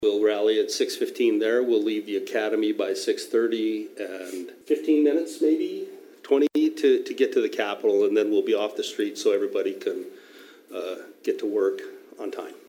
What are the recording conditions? spoke before the Pierre City Commission on Tuesday.